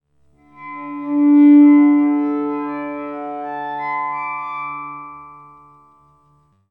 Source: Resonated harmonic gliss on D (5:15-7:27)
Res_Harmonic_D.aiff